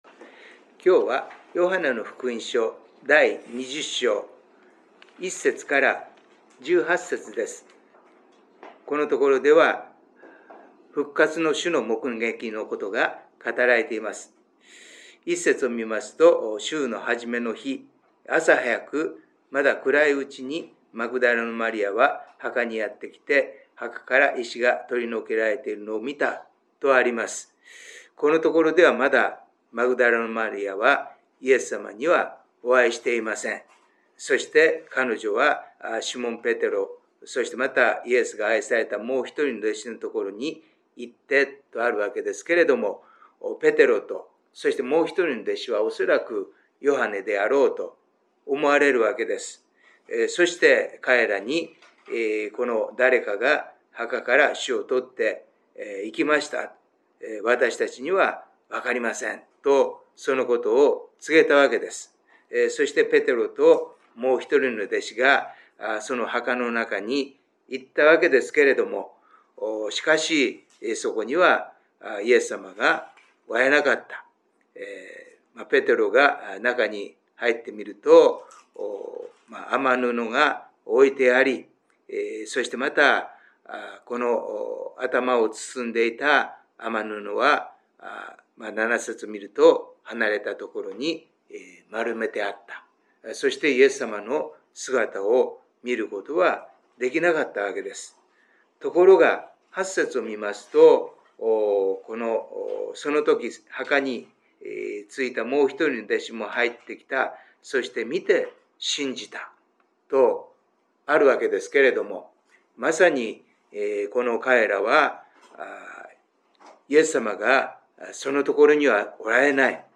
音声メッセージです。